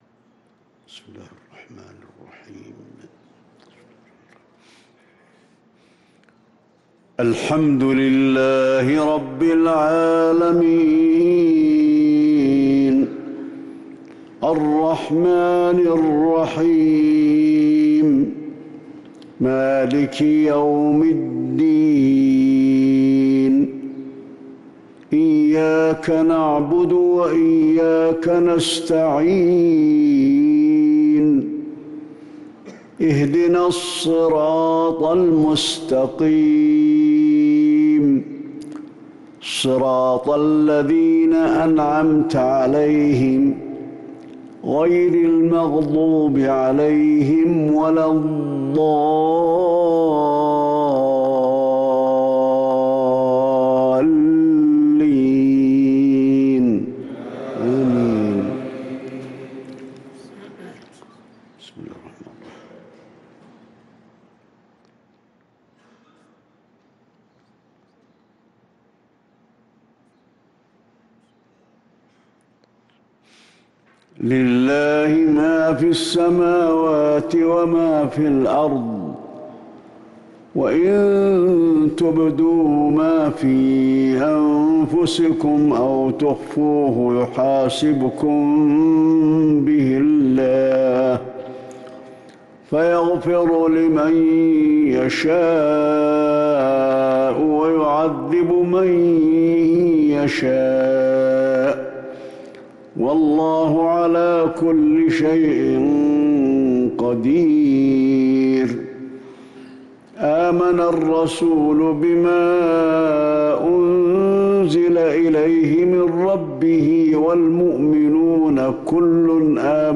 صلاة المغرب للقارئ علي الحذيفي 1 شوال 1444 هـ